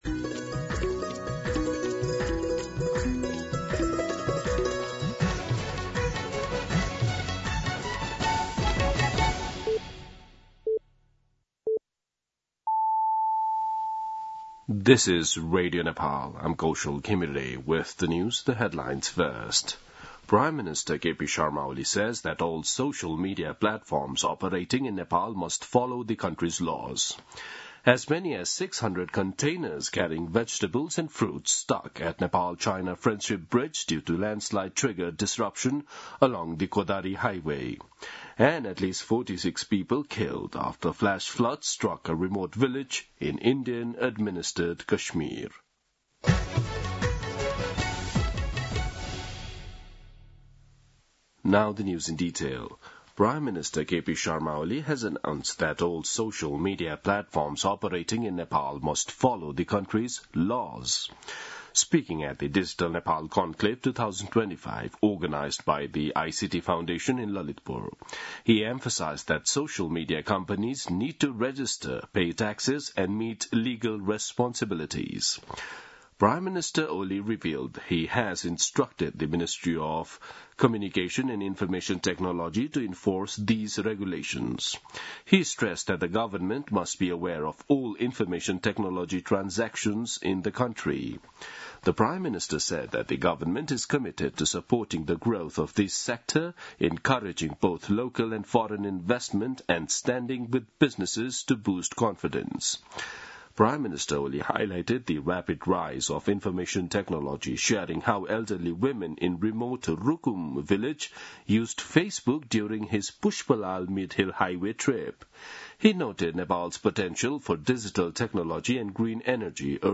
दिउँसो २ बजेको अङ्ग्रेजी समाचार : ३० साउन , २०८२
2-pm-English-News-3.mp3